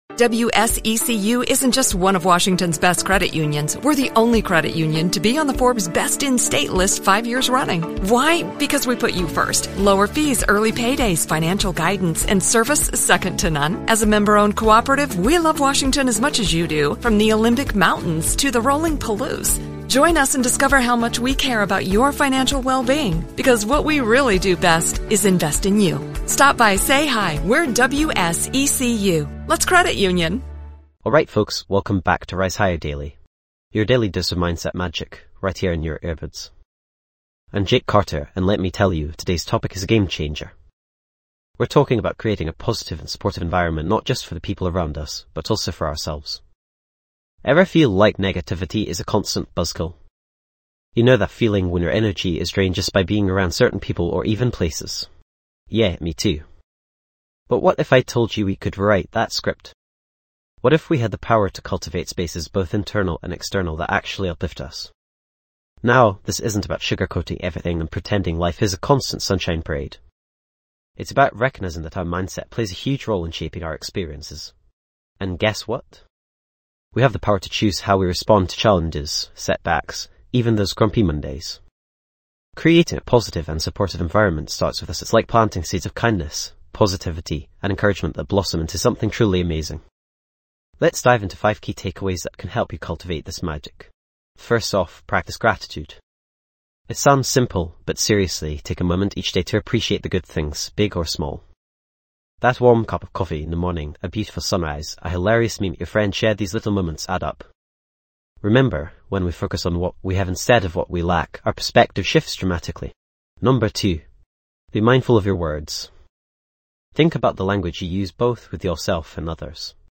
Podcast Category:. Personal Development
This podcast is created with the help of advanced AI to deliver thoughtful affirmations and positive messages just for you.